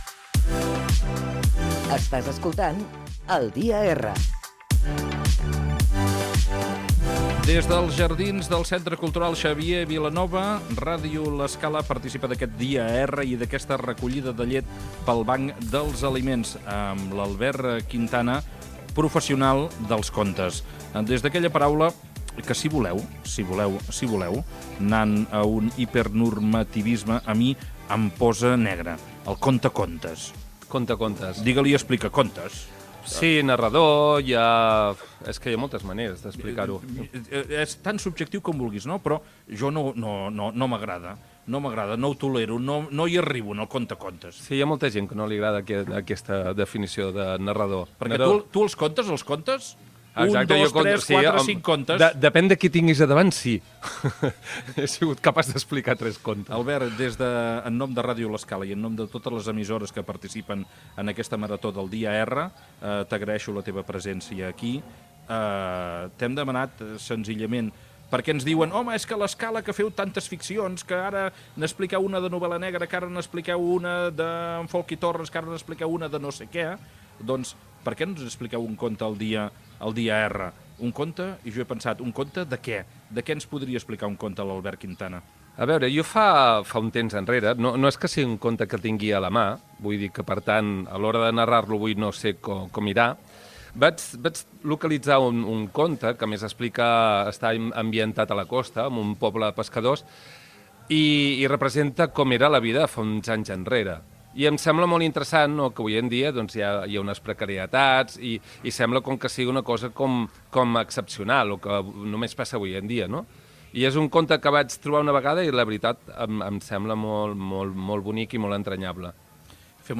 Des del jardins del Centre Cultural Xavier Vilanova de l'Escala.
Entreteniment